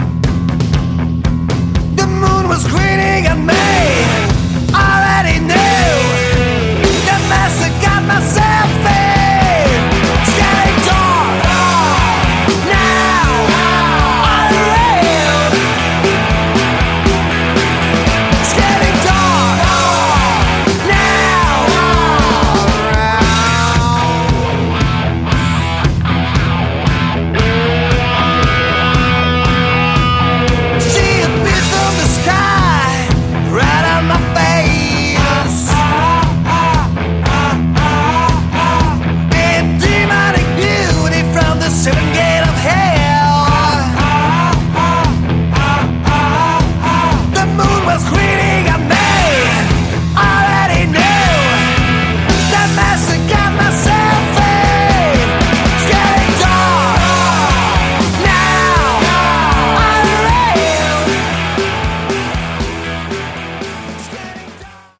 Category: Sleazy Hard Rock
vocals
bass
drums
guitars